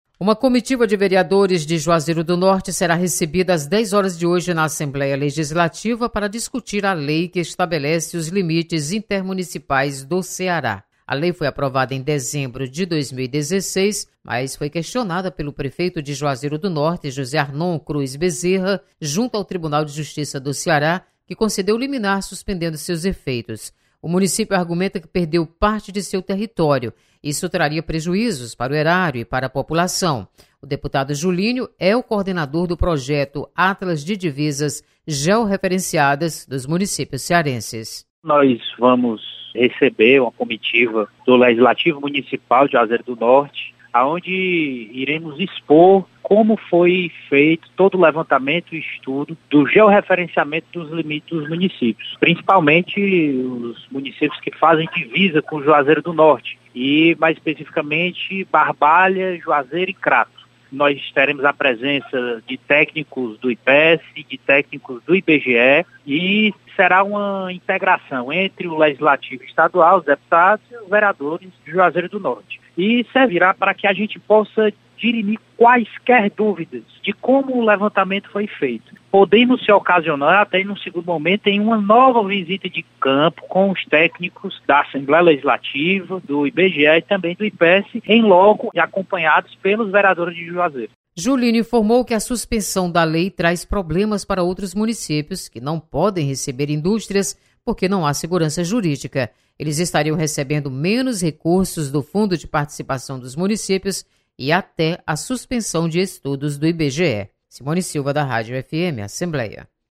Deputado Julinho coordena projeto Atlas de Divisas. Repórter